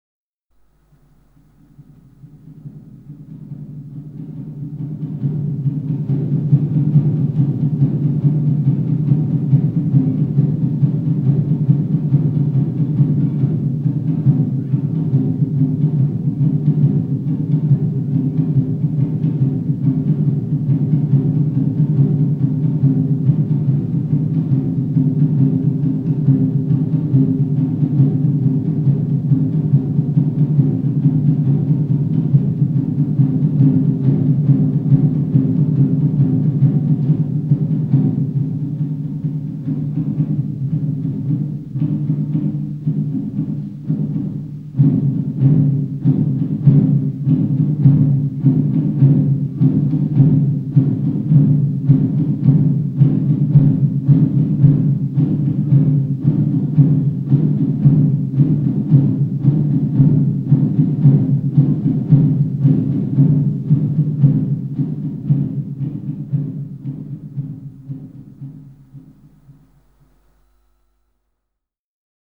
Tambores de guerra